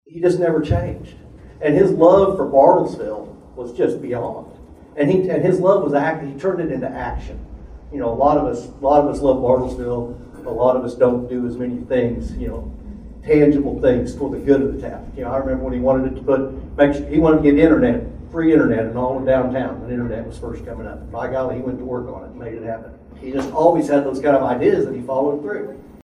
State Rep. John B. Kane